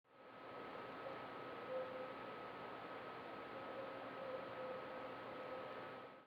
Best specimen ( Hear the "ping" )